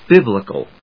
音節bib・li・cal 発音記号・読み方
/bíblɪk(ə)l(米国英語)/